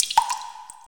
SI2 WATERD0A.wav